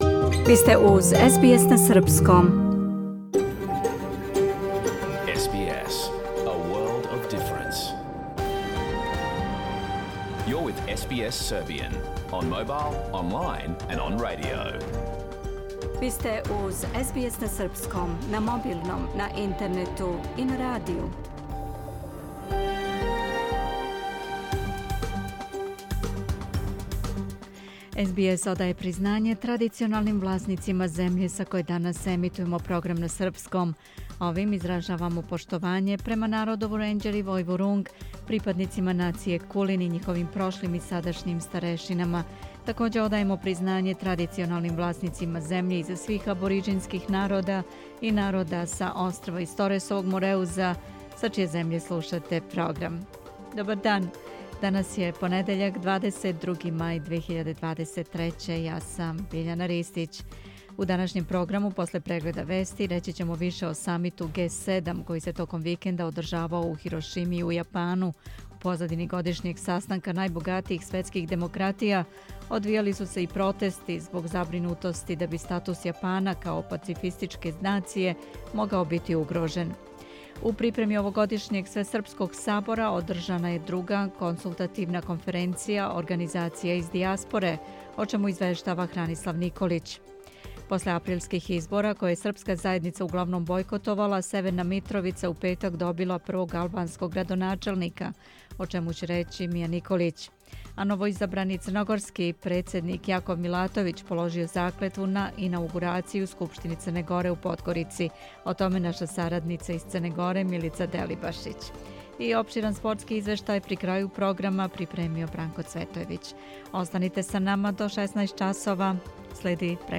Програм емитован уживо 22. маја 2023. године
Ако сте пропустили данашњу емисију, можете да је слушате у целини као подкаст, без реклама.